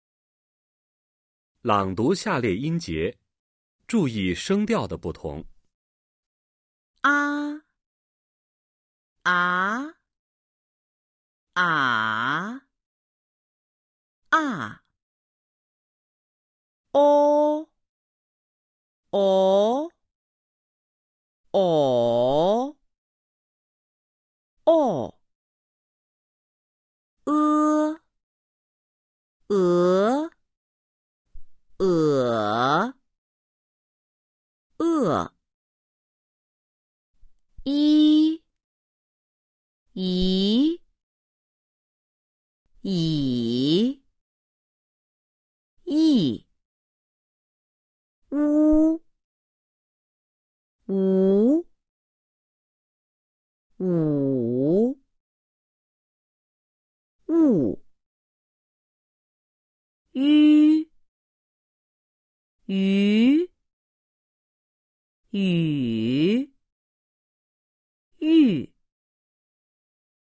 Thanh điệu của tiếng Trung Quốc (bốn thanh)